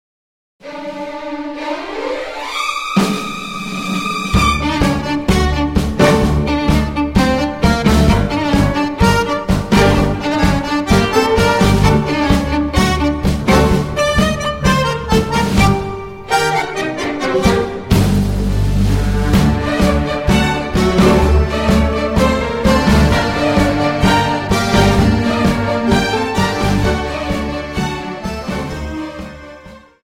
Dance: Tango Song